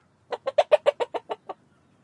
描述：母鸡咯咯叫。PCM M10内部话筒，在大加那利岛的Utiaca附近录制
标签： 咯咯 现场录音 母鸡 鸡舍
声道立体声